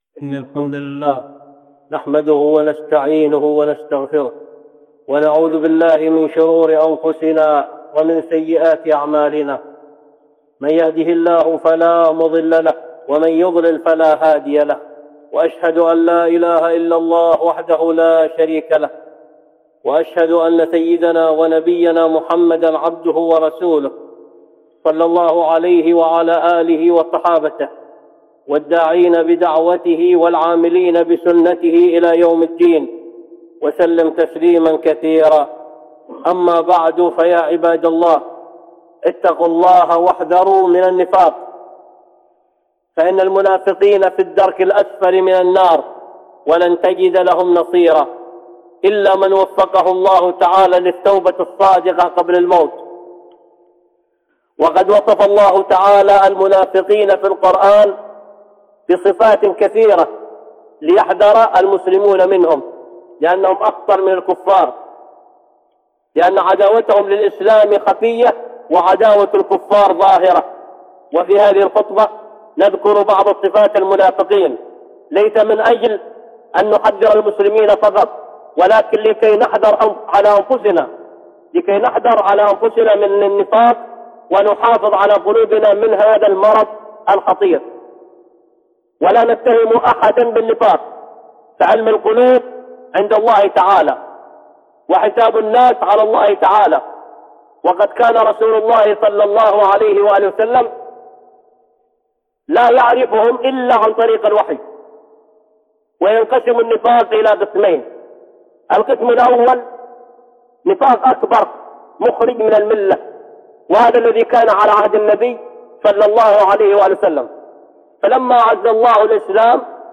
(خطبة جمعة) من صفات المنافقين